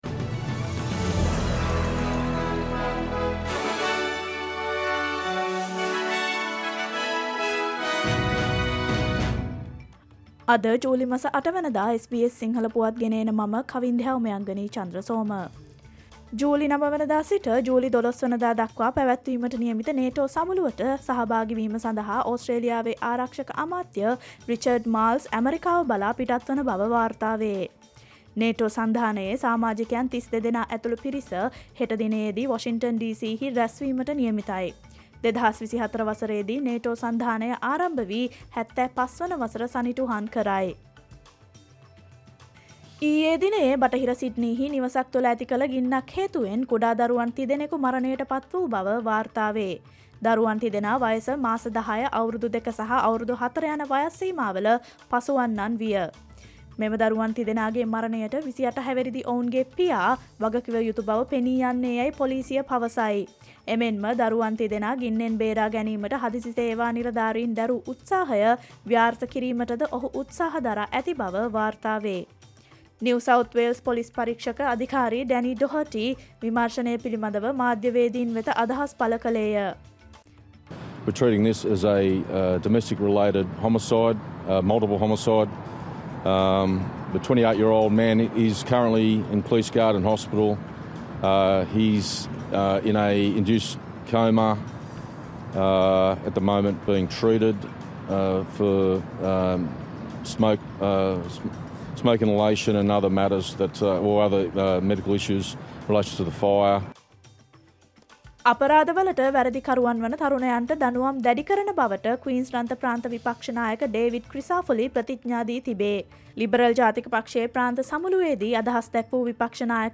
Australia's news in English, foreign and sports news in brief.